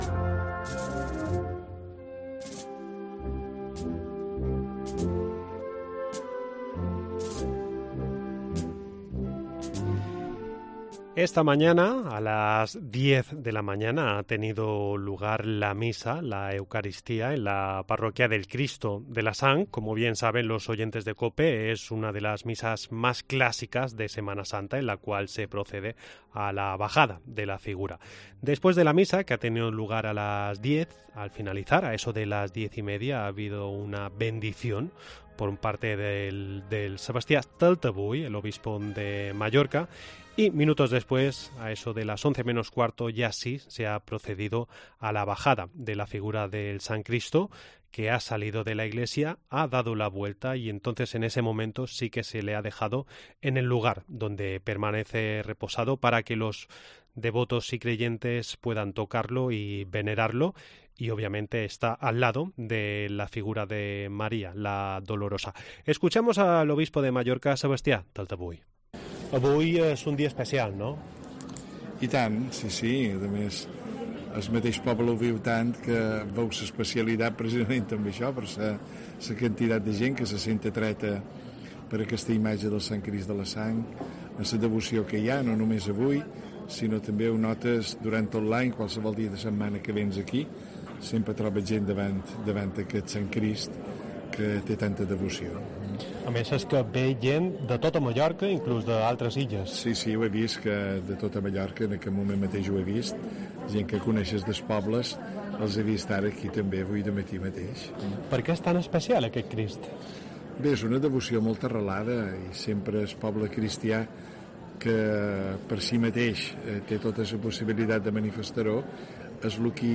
Asistimos a la misa en la capilla de La Sang para hablar con autoridades como Sebastià Taltavull, Obispo de Mallorca. También hemos palpado las sensaciones entre los creyentes